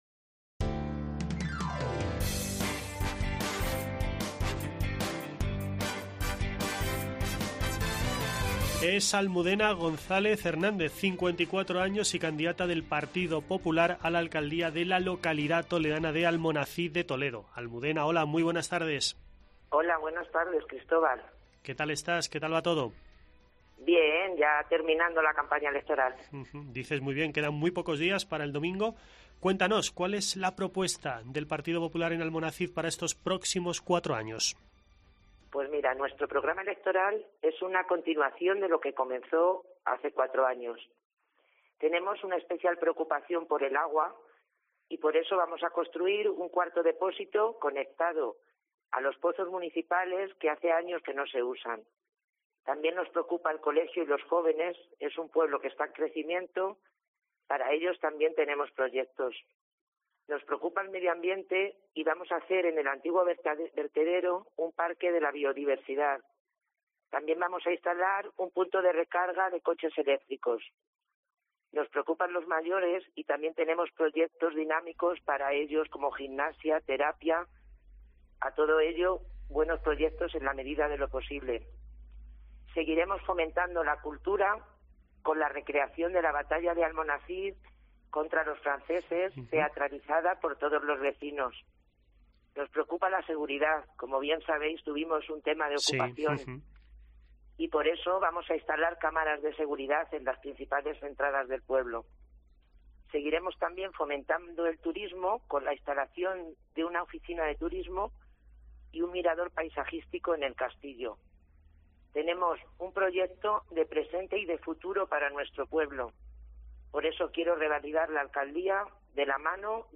AUDIO: Entrevista a Almudena González, alcaldesa de Almonacid de Toledo y candidata a la reelección